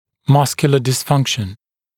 [‘mʌskjulə dɪs’fʌŋkʃn][‘маскйулэ дис’фанкшн]мышечная дисфункция